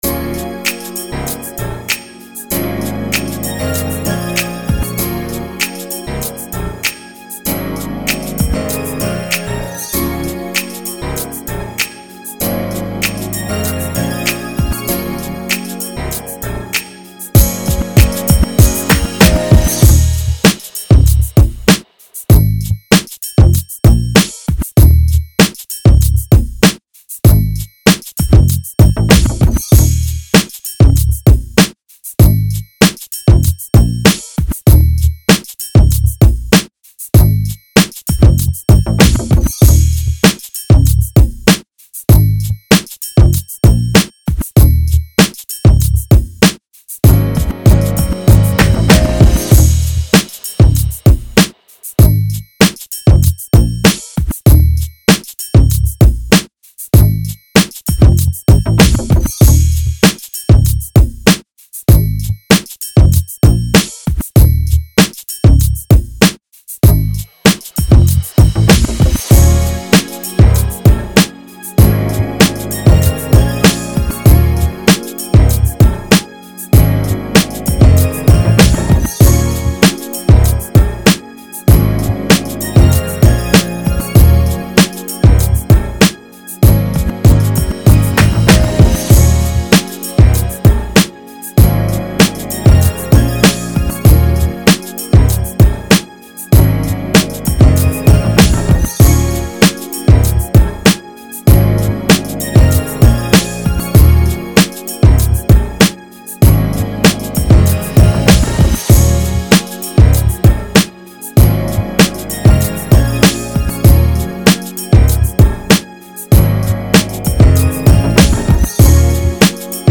R&B, 90s
Bb minor